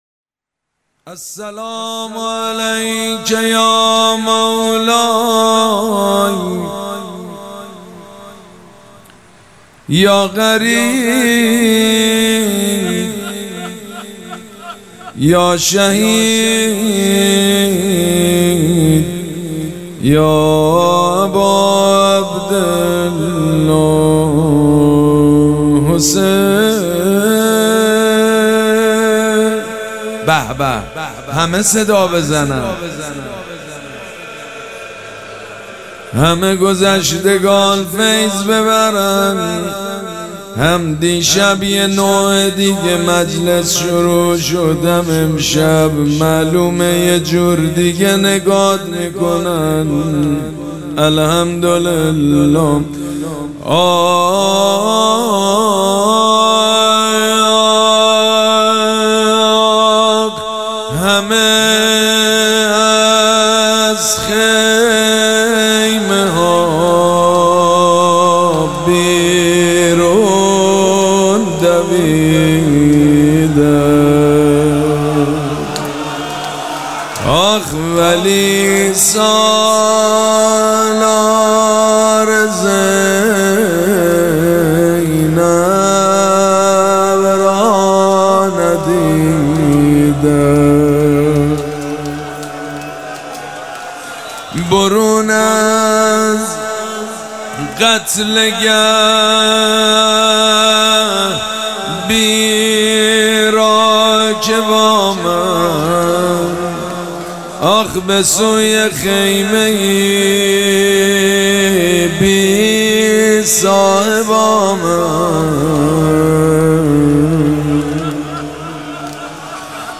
شب دوم مراسم عزاداری اربعین حسینی ۱۴۴۷
روضه
حاج سید مجید بنی فاطمه